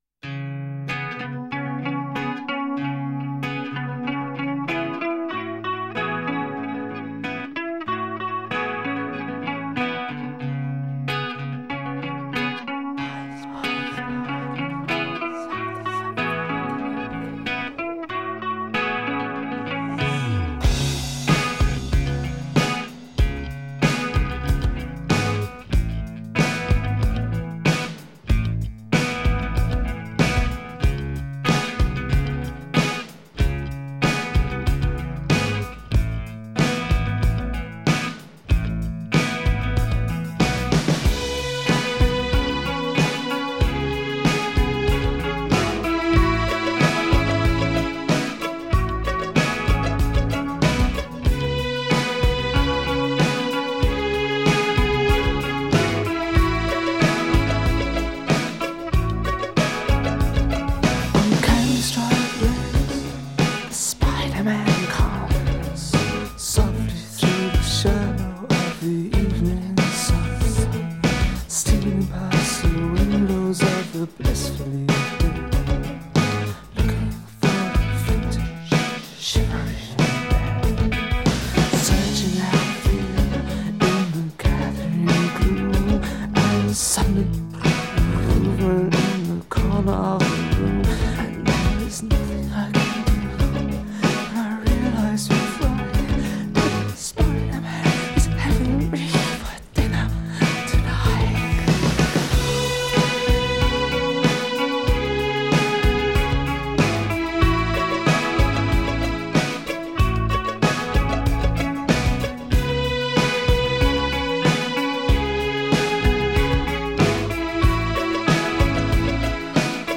آهنگ گوتیک